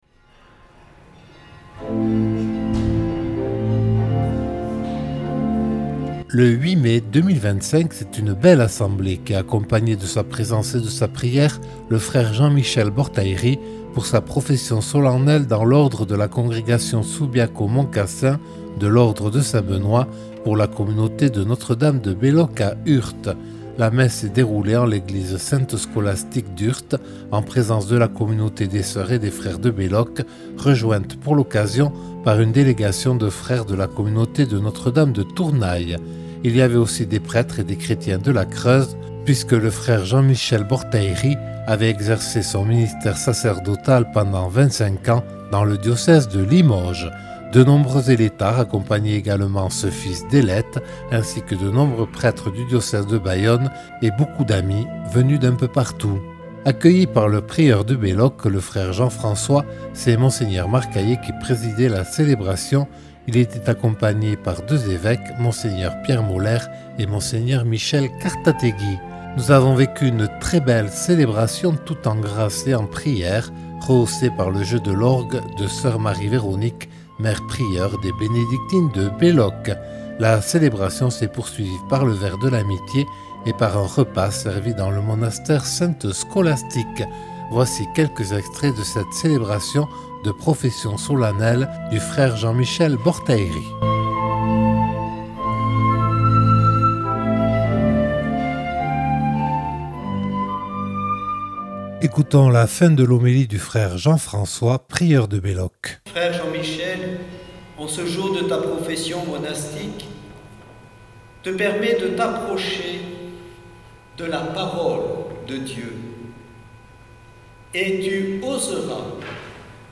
La messe s’est déroulée en l’église Sainte-Scholastique d’Urt en présence de la communauté des sœurs et des frères de Belloc rejointe pour l’occasion par une délégation de frères de la communauté de Notre-Dame de Tournay.